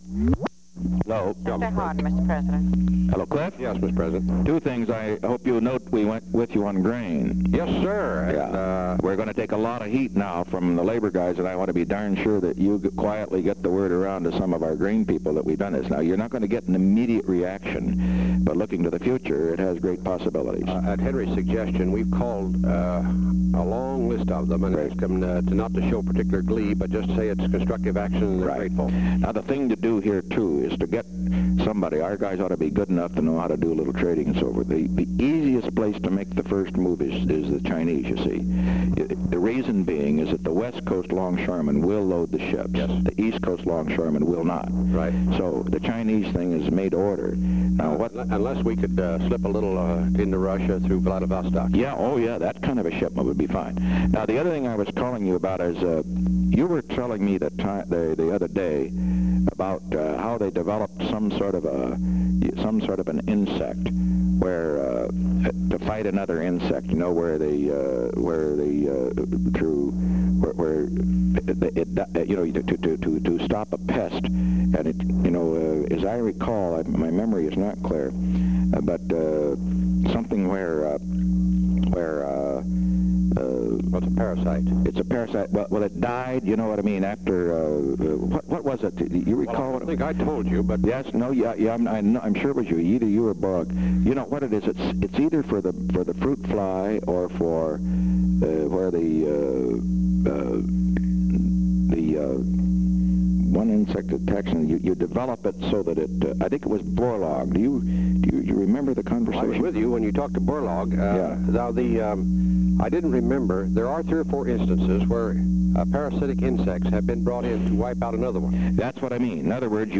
Secret White House Tapes
Location: White House Telephone
The President talked with Clifford M. Hardin.